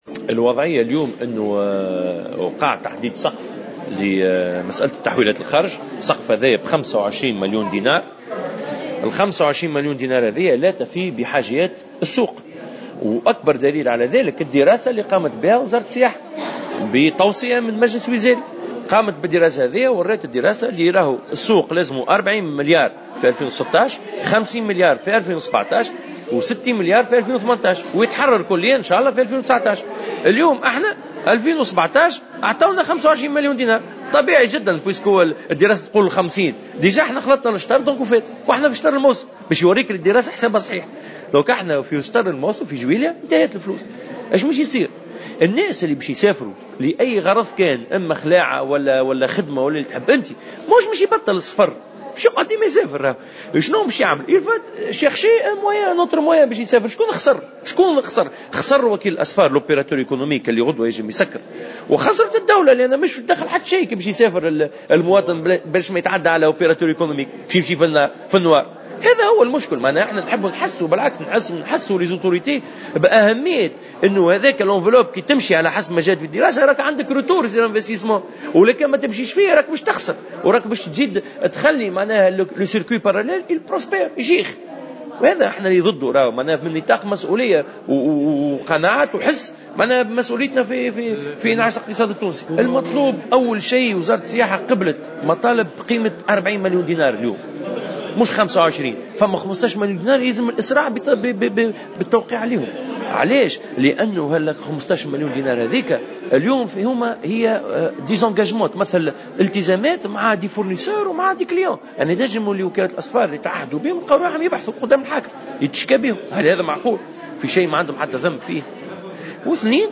في تصريح لمراسل الجوهرة اف ام خلال ندوة صحفية عقدتها الجامعة اليوم الاثنين